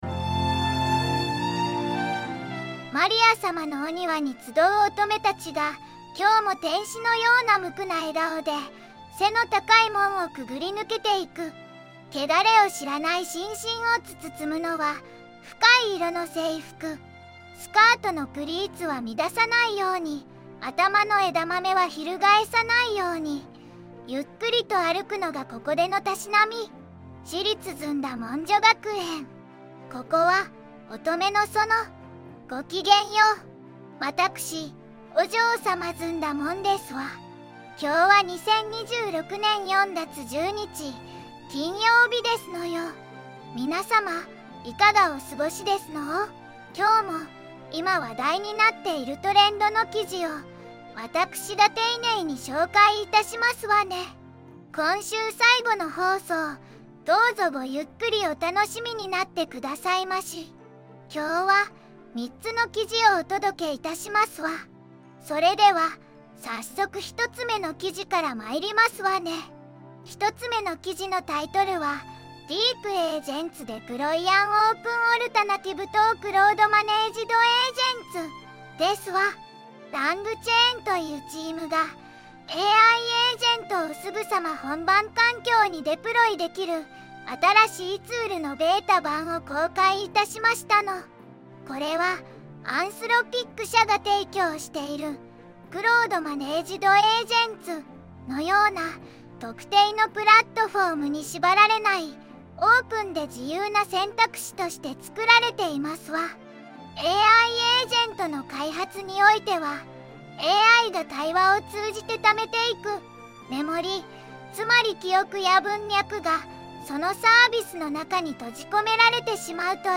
お嬢様ずんだもん
VOICEVOX:ずんだもん